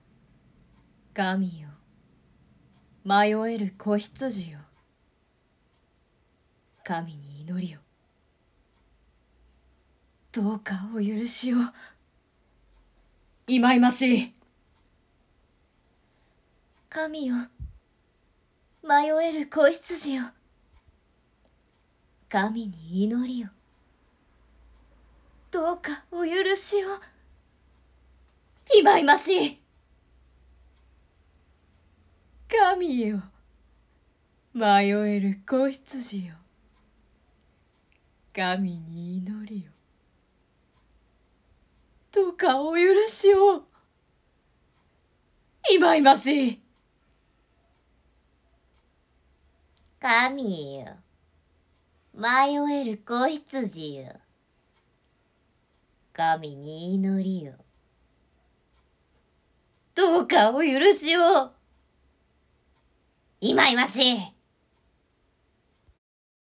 シスター風ガヤ